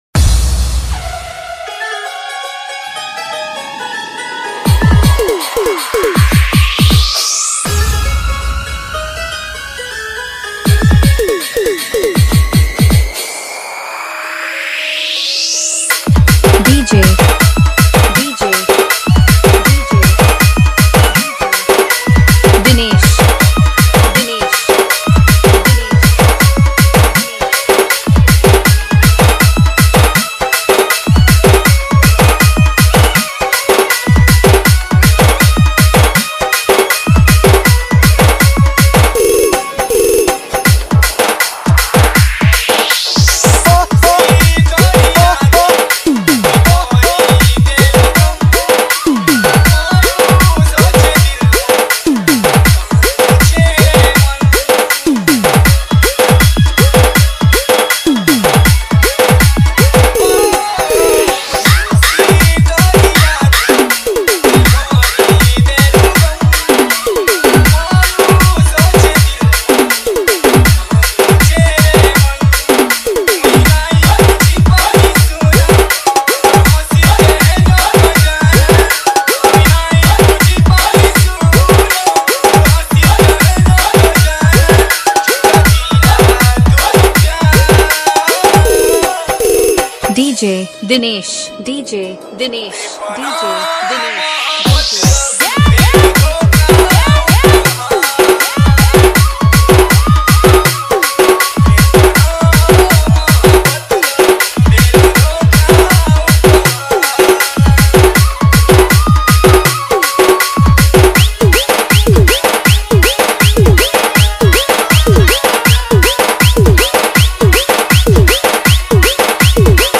Category:  Sambalpuri Dj Song 2022